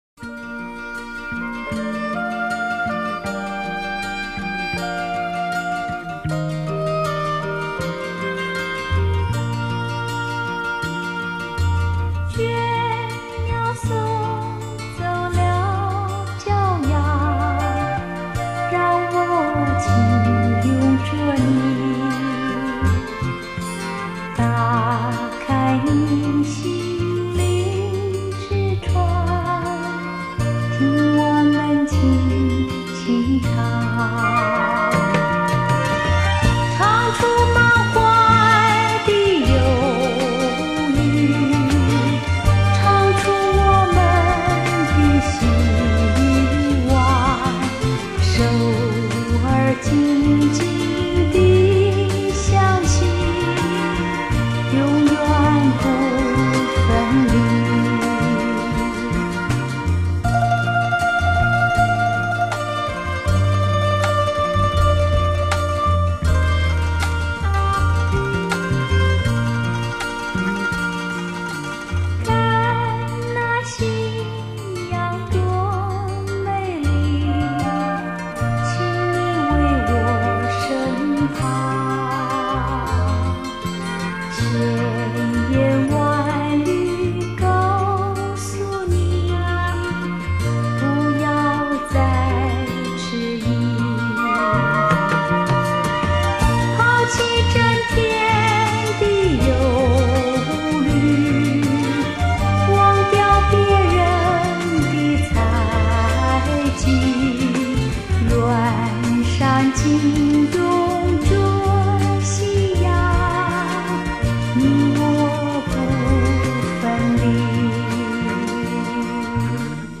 地　　区：台湾
八张黄金版CD之100首曲目，全以最新24位元数位录音技术处理，重现当年歌手原声重唱，音色更为甘亮饱满。